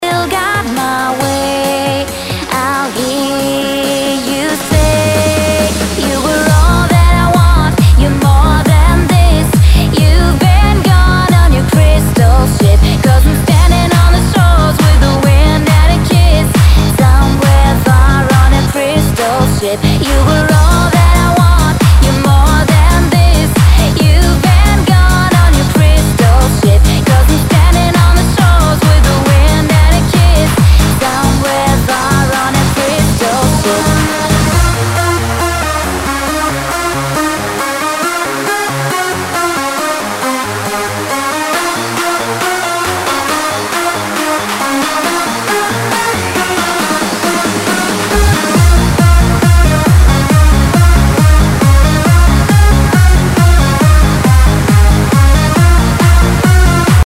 HOUSE/TECHNO/ELECTRO
ユーロ・ヴォーカル・ハウス！